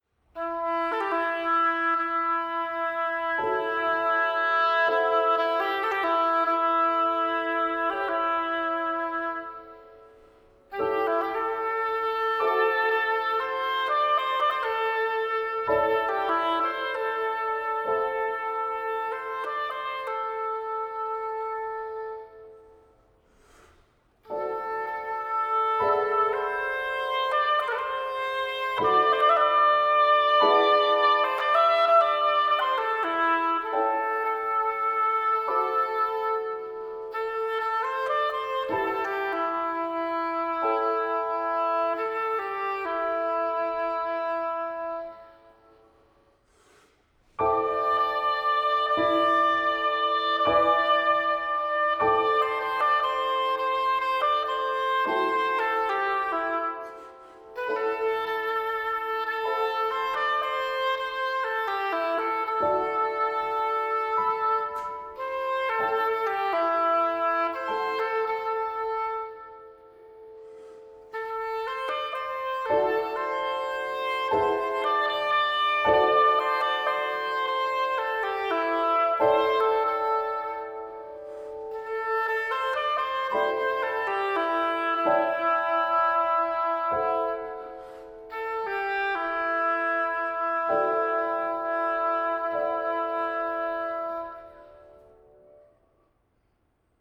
a lyrical oboe-piano duo
piano